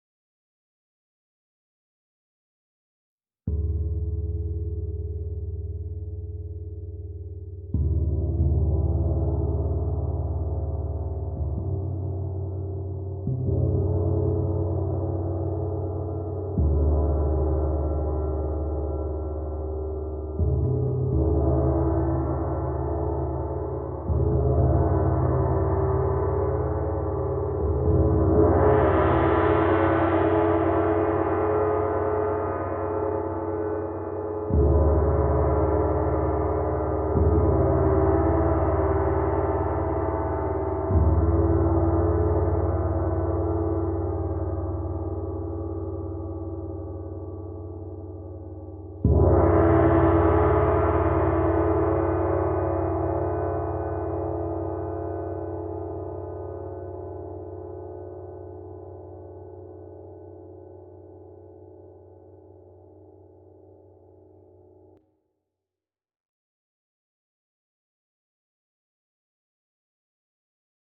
Meinl Sonic Energy 22" Soundscape Gong, Metatron’s Cube (SGMC22)
Feature: Rich, dynamic soundscapeFeature: Polished surface for a luxurious shineFeature: Easily playable edgeFeature: High-quality engraved symbolFea…